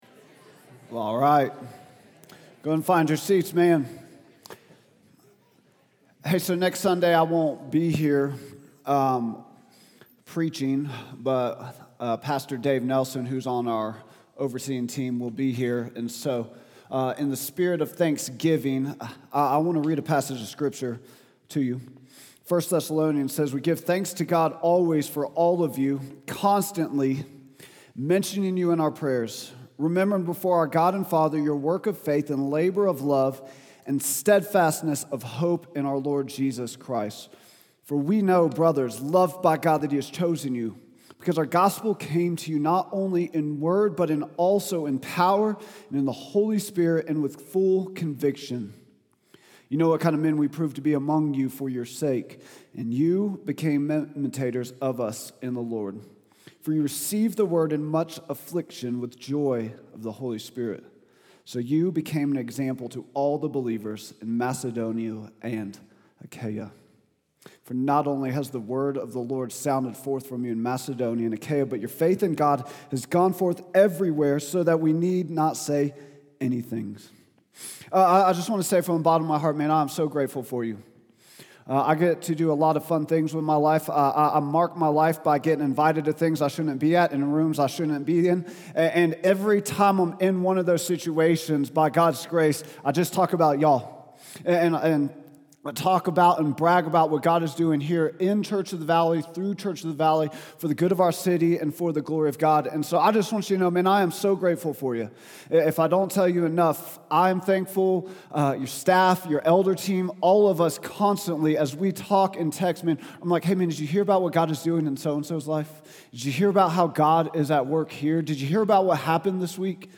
preaches on Acts 11:18-30.